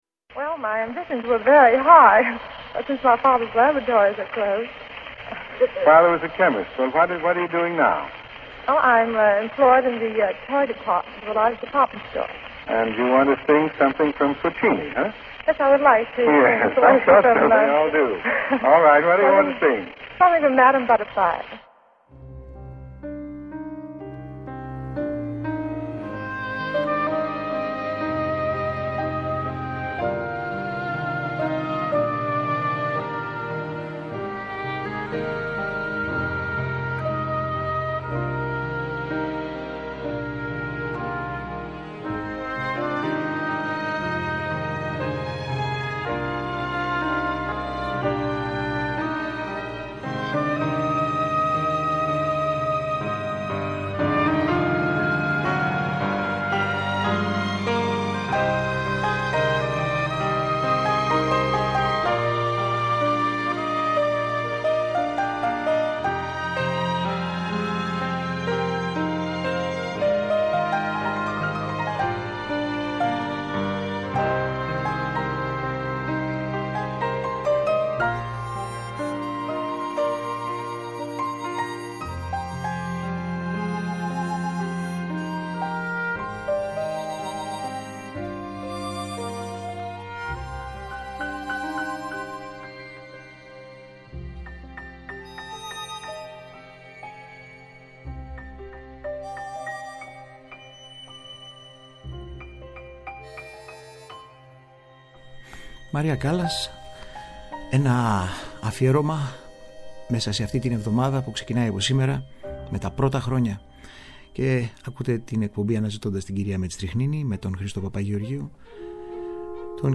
Μία σειρά πέντε ωριαίων εκπομπών με ηχογραφήσεις και αφηγήσεις όπως αυτές αποτυπώνονται μέσα από την πλούσια βιβλιογραφία για την μεγάλη αυτή προσωπικότητα της κοινωνικής ζωής και της τέχνης του 20ου αιώνα.